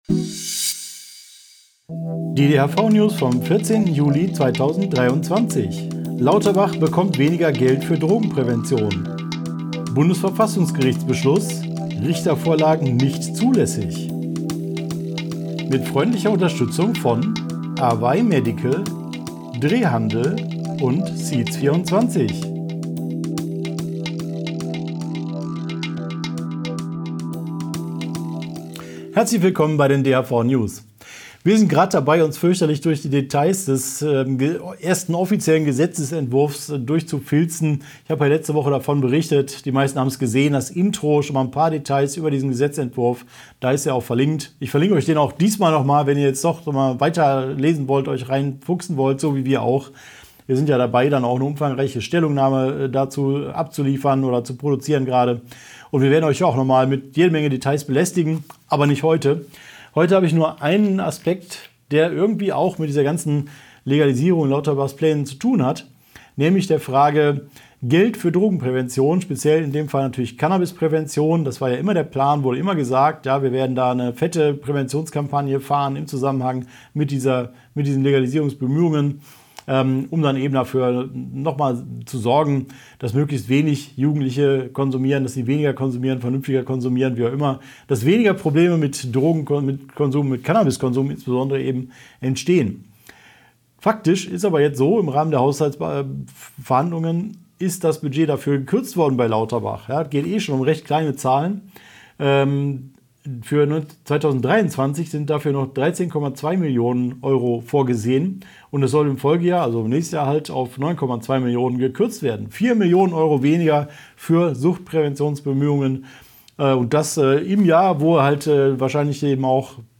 DHV-Video-News #385 Die Hanfverband-Videonews vom 14.07.2023 Die Tonspur der Sendung steht als Audio-Podcast am Ende dieser Nachricht zum downloaden oder direkt hören zur Verfügung.